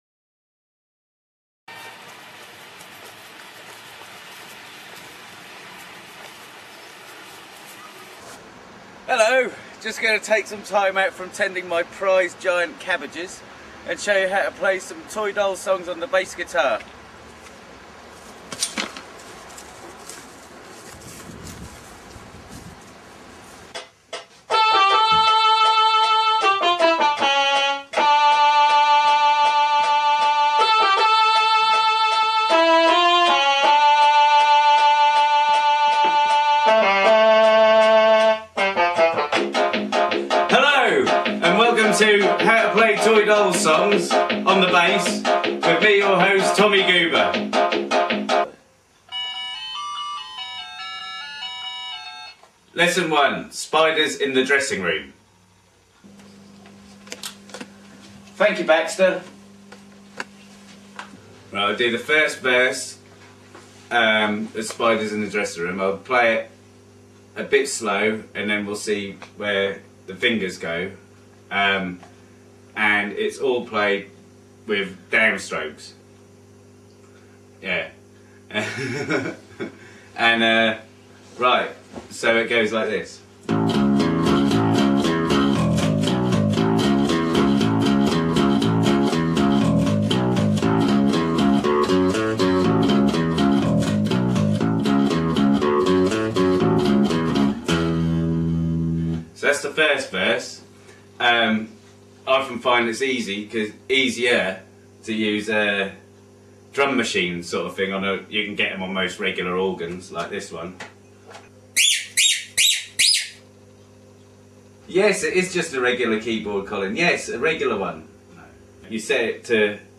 Download 8 min 10 sec (25.69MB)   Bass Lesson 1 - Spiders In The Dressing Room